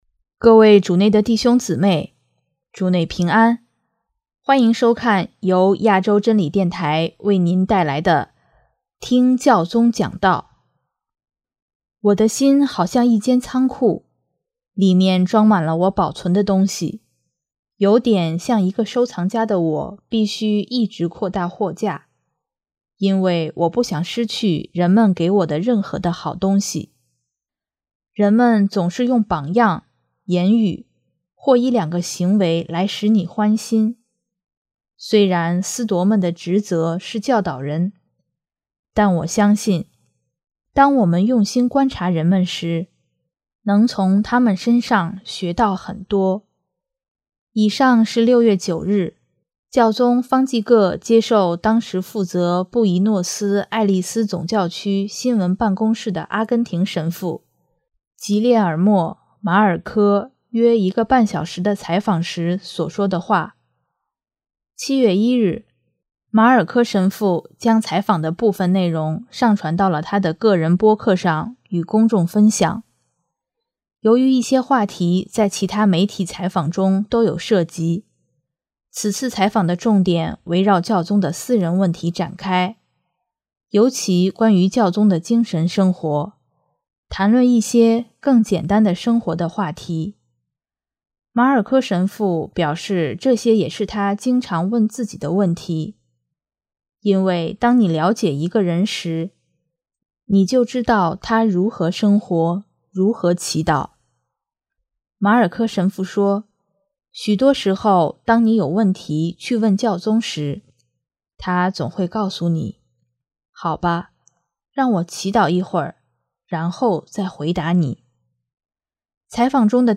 首页 / 听教宗讲道/ 新闻/ 教宗方济各